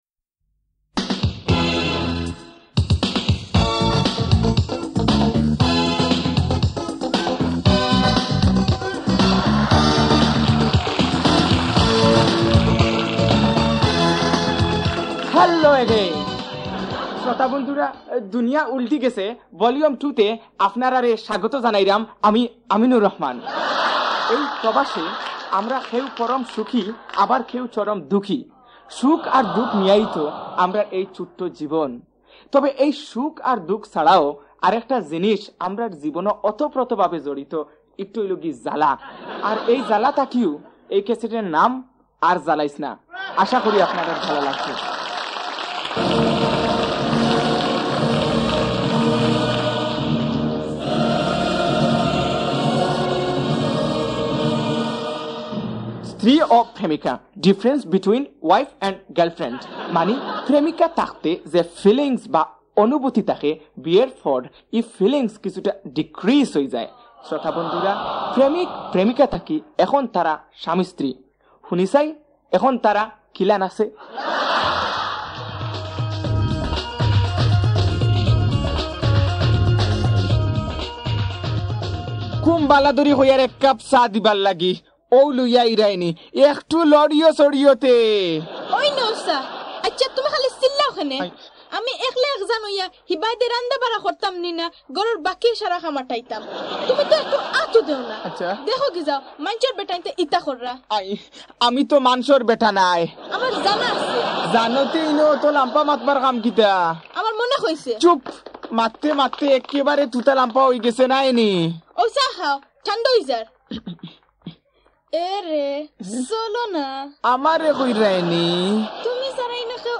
Jhala Part 1 – Comedy